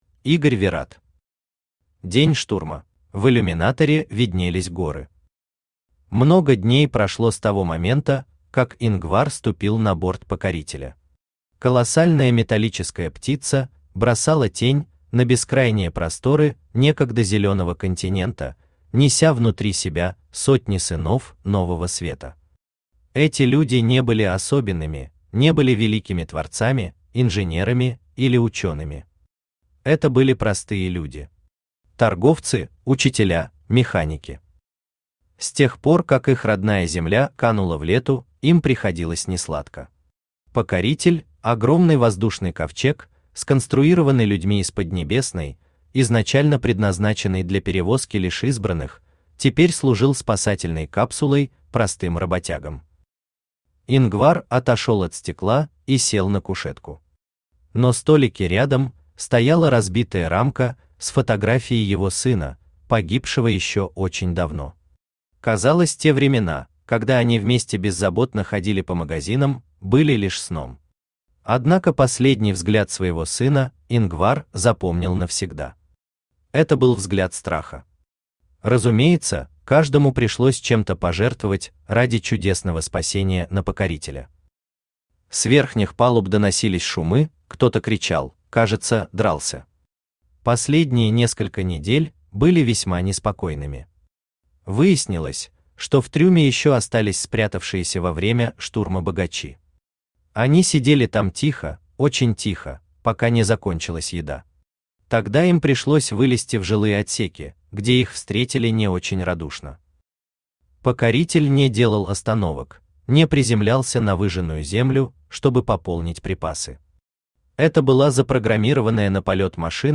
Aудиокнига День штурма Автор Игорь Анатольевич Вират Читает аудиокнигу Авточтец ЛитРес. Прослушать и бесплатно скачать фрагмент аудиокниги